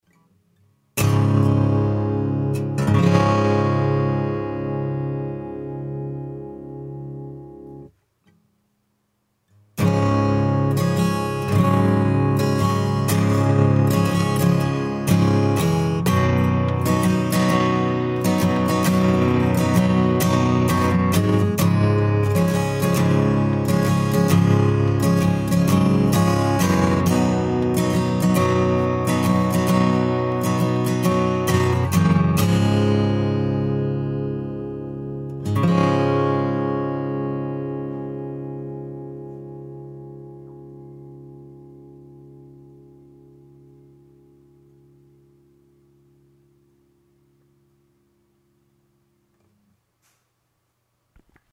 早速、チューニングして、ジャラ～ンとストロークで弾いてみましょう。
♪ストローク
ちゃんと音が出ています。
ただ、あまりに音が大きいので少しおとなしく弾きました。